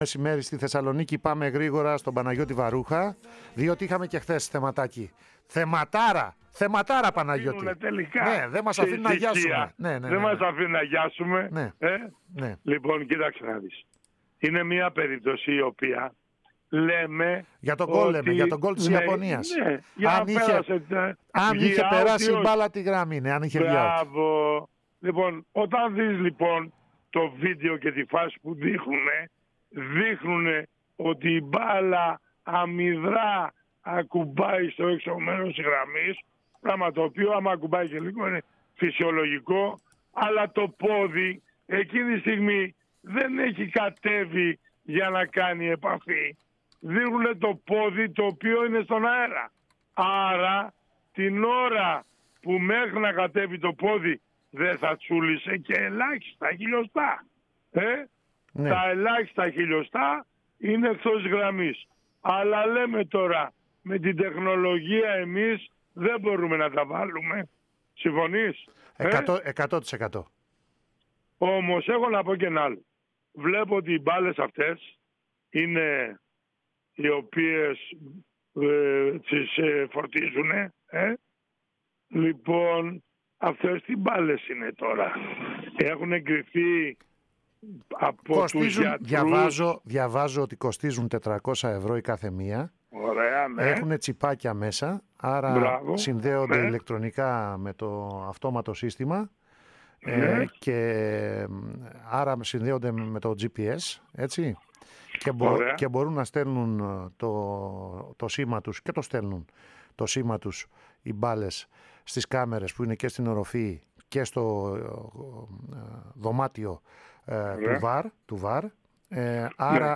Ο παλαίμαχος διαιτητής μίλησε στην εκπομπή